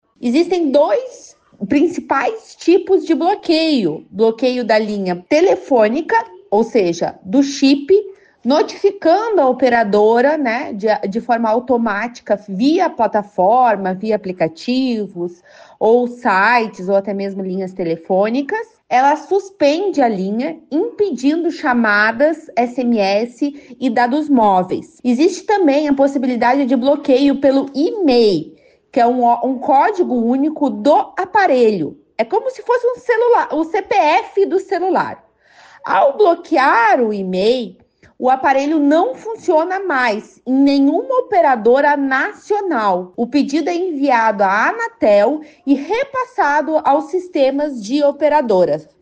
A advogada, especialista em direito digital e crimes cibernéticos